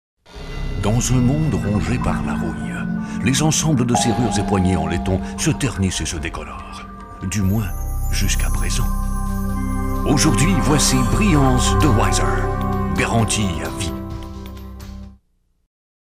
英語/北米・オセアニア地方 男性
見た目同様の優しい声が特徴的！